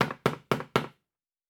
Nail 3_1.wav